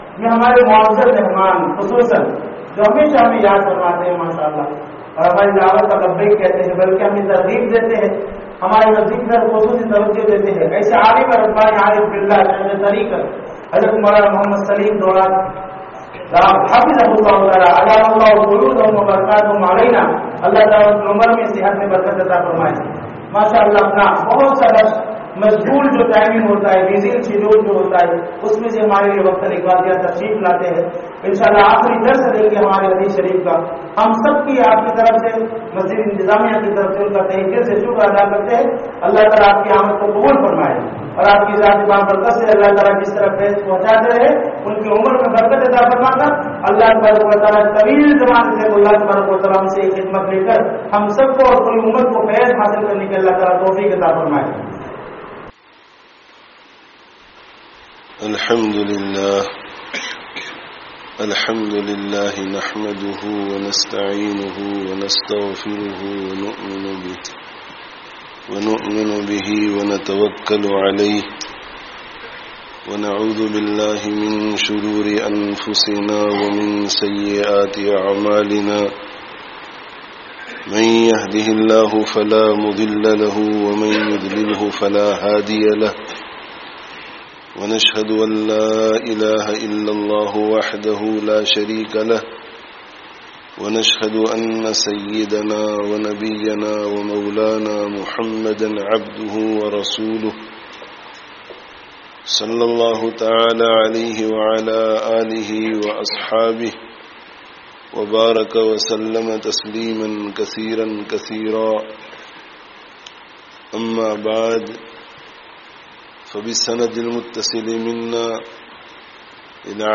'Ilm Sīkho, Us Par 'Amal Karo Awr Āgey Phonchāwo (Khatme Bukhārī, Jaame Masjid, Blackburn 15/07/18)